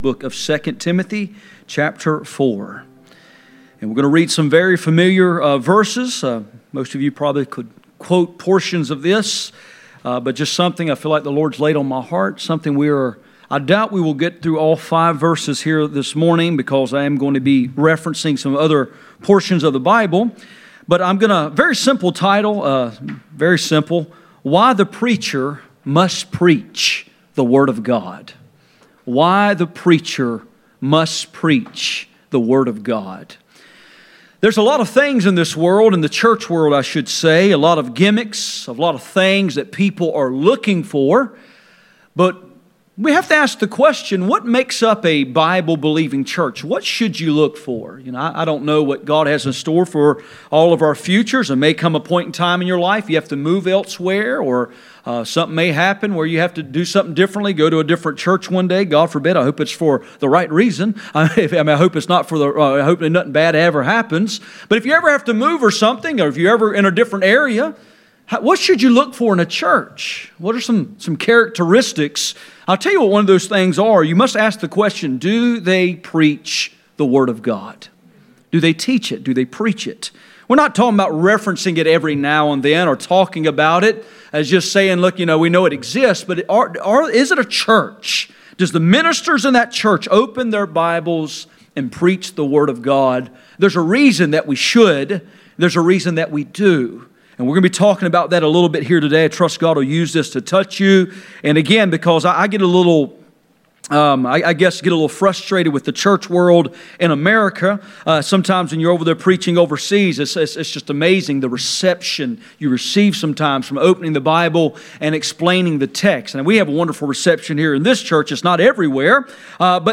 Passage: 2 Timothy 4:1-5 Service Type: Sunday Morning